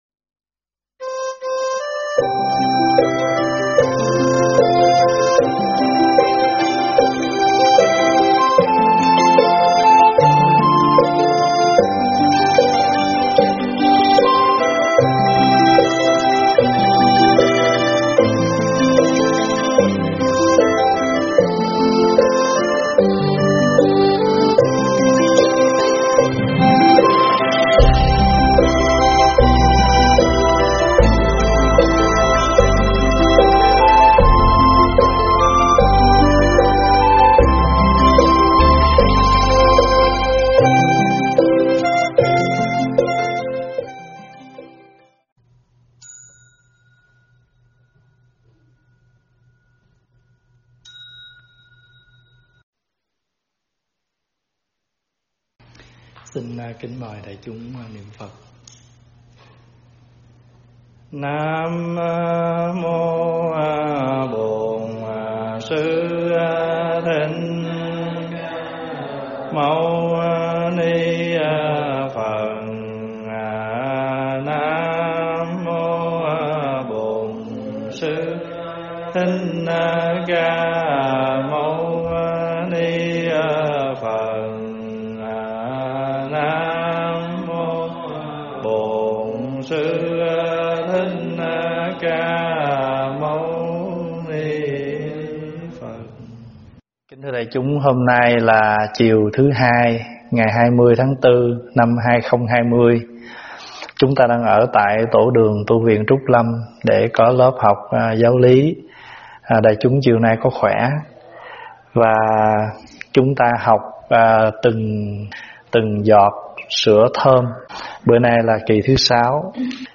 thuyết pháp
giảng tại Tv Trúc Lâm